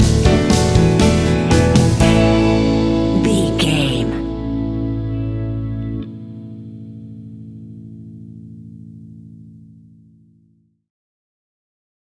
Ionian/Major
pop rock
indie pop
fun
energetic
uplifting
instrumentals
upbeat
groovy
guitars
bass
drums
piano
organ